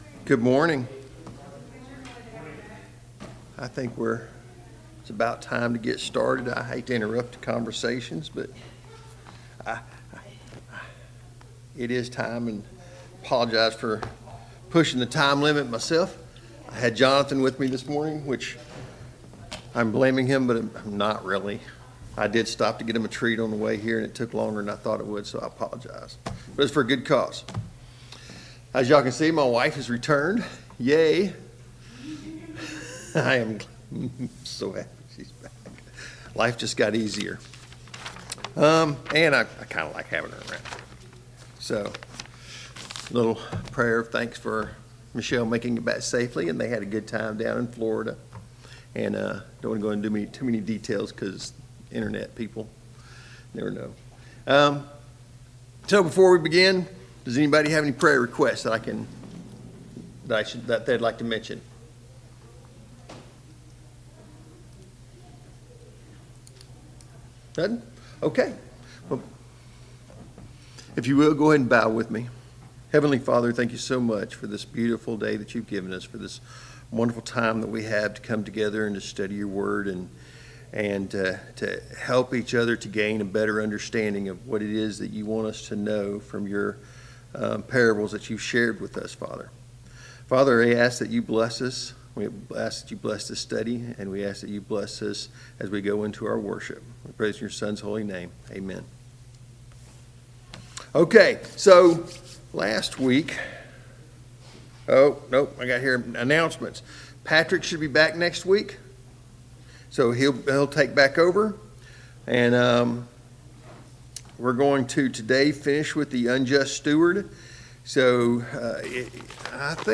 A Study on the Parables Service Type: Sunday Morning Bible Class « 2.